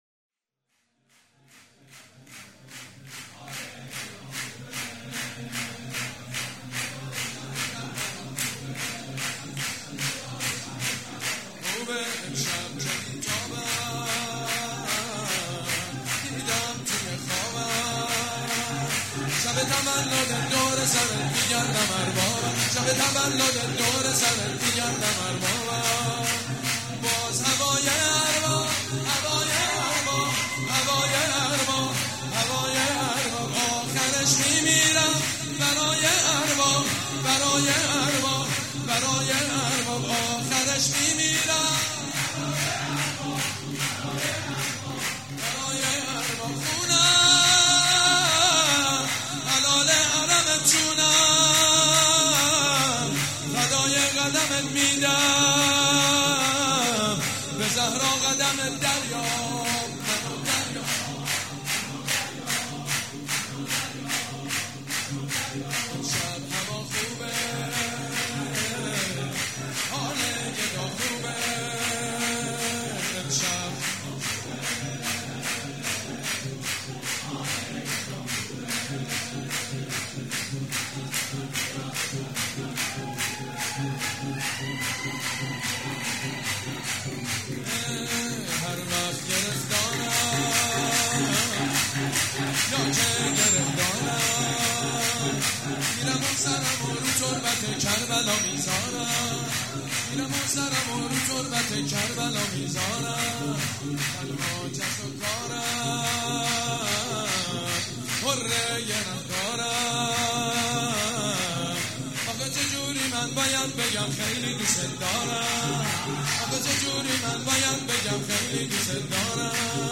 شور: امشب چه بی تابم، دیدم توی خوابم